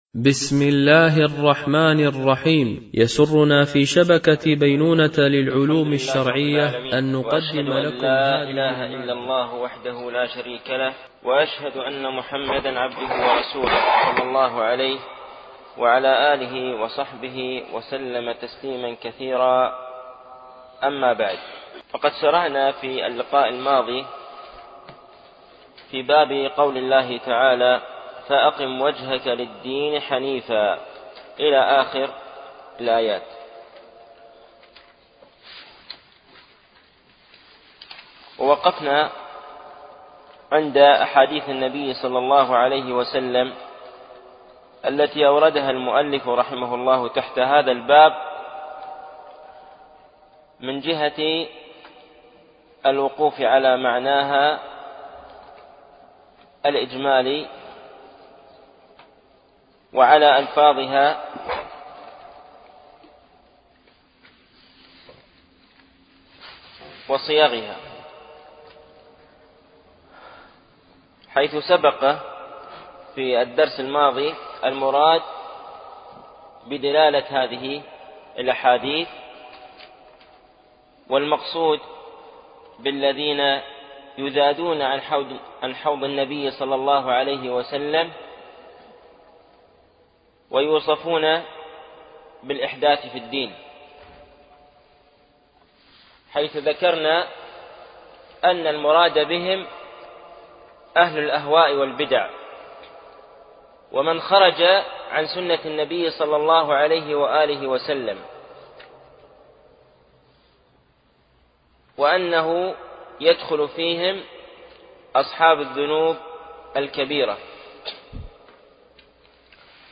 شرح كتاب فضل الاسلام ـ الدرس العاشر